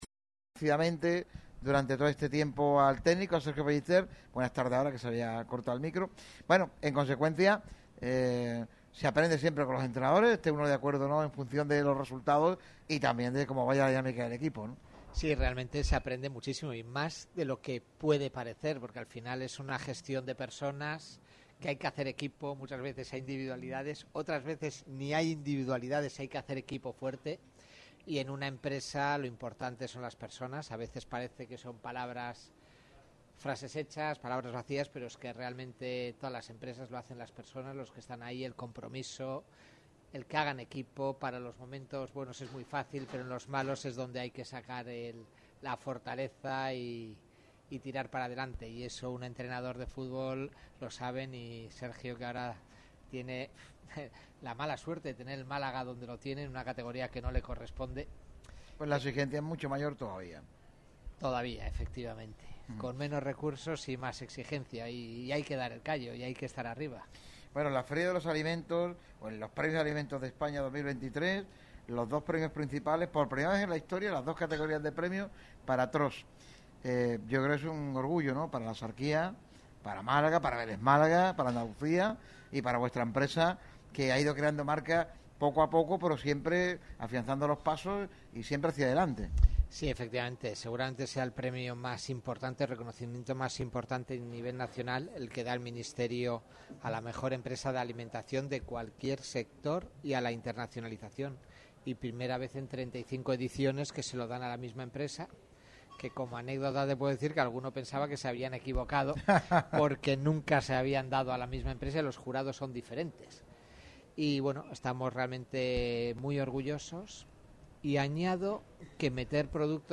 Una vez más, Araboka Plaza abre las puertas de su espectacular negocio para todo el equipo de Radio MARCA Málaga.